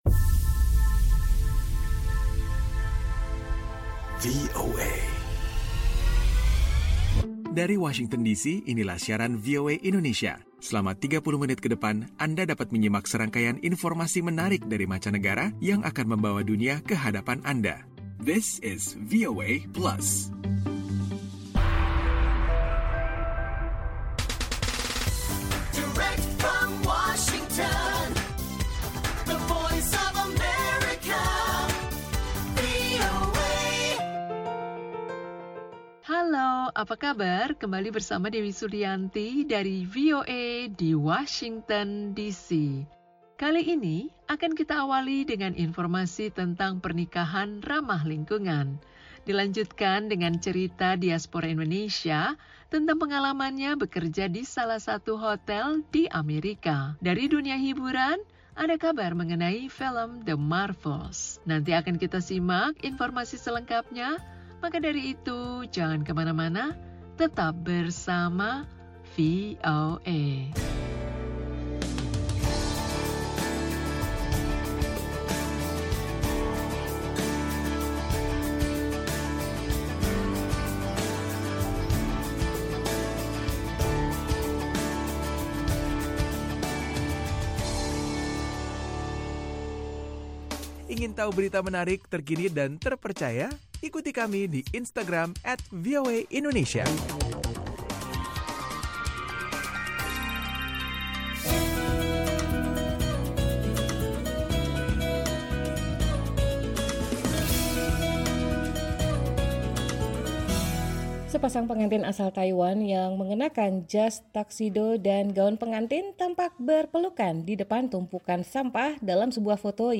VOA Plus kali ini akan menggelar info seputar proses pernikahan yang bersifat ramah lingkungan di Taiwan. Ada pula obrolan bersama seorang diaspora Indonesia di Arizona yang bekerja di bidang perhotelan.